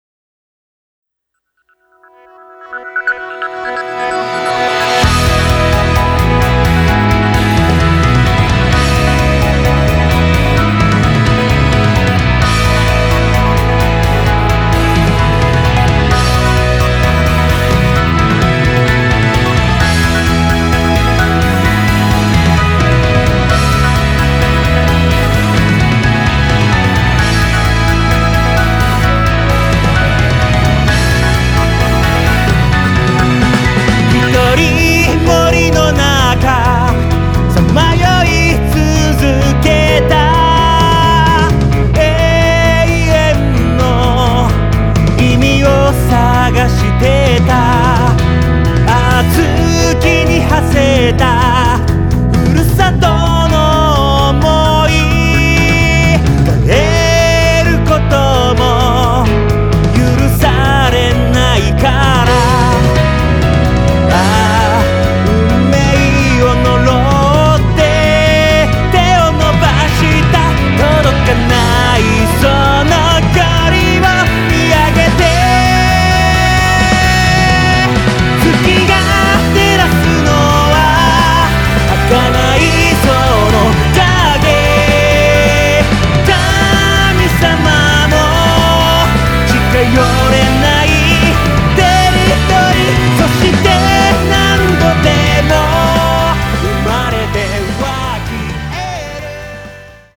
クロスフェードデモ
ギター炸裂・ベース爆裂・ドラム激烈のロックアレンジから極上バラードまで、